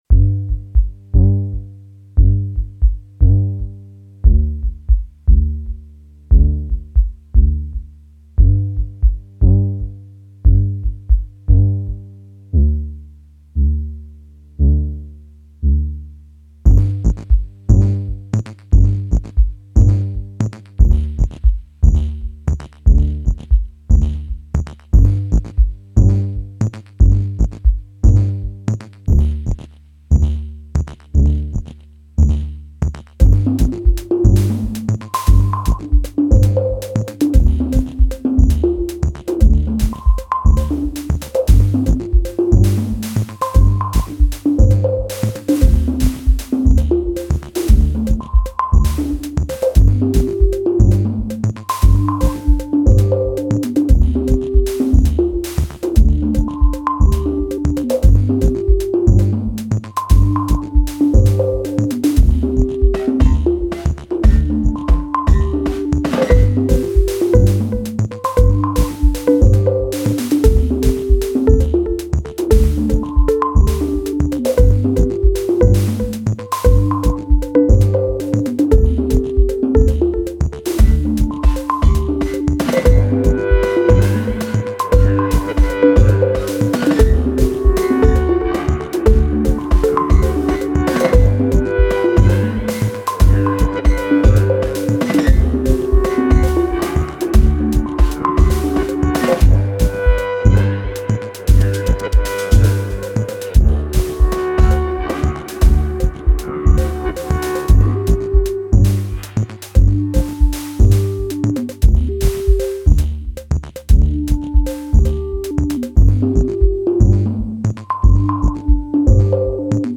DT sampling DFAM, TD-3, Matriarch and Per4mer (thru euro-fx-stuff).